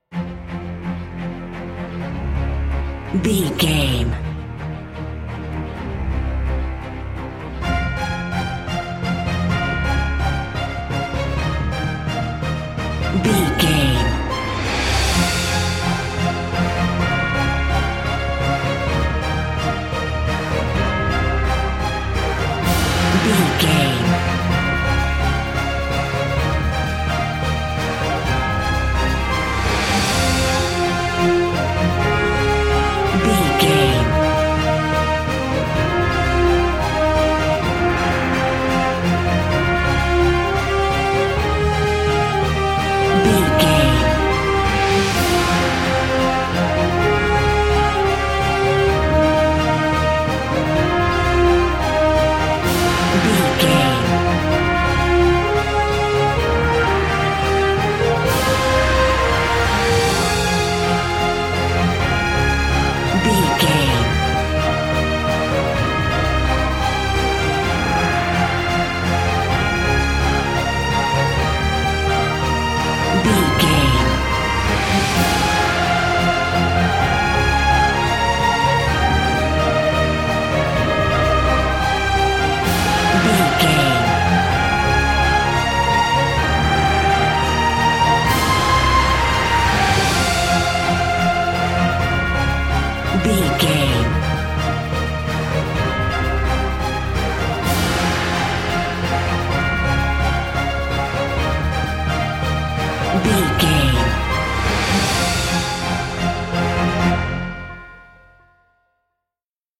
Epic / Action
Fast paced
Aeolian/Minor
hybrid
brass
cello
horns
strings
synthesizers